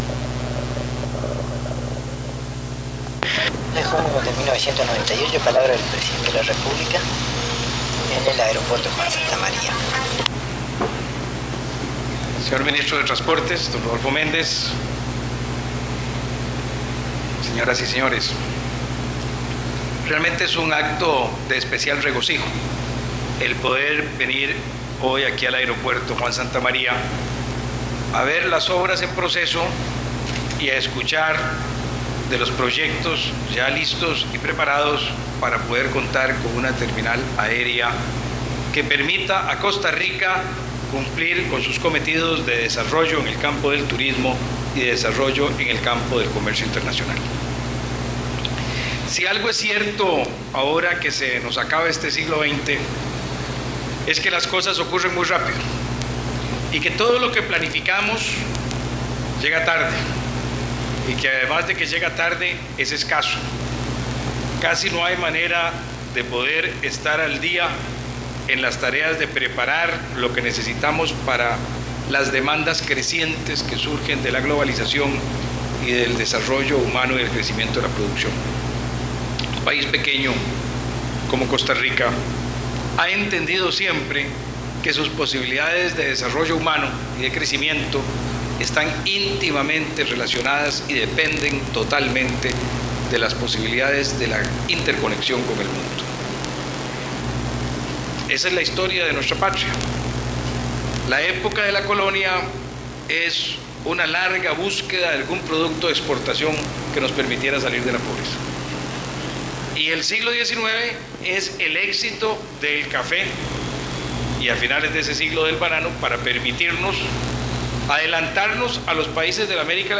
Discursos del Presidente de la República sobre obras en proceso en el aeropuerto Juan Santamaría, V Congreso de la Industria Alimentaría, Cámara de Productores de Aplicaciones Informáticas, Acto de Concesión de Obras Públicas y policía comunitaria en Jardines de Alajuela - Archivo Nacional de Costa
Casette de audio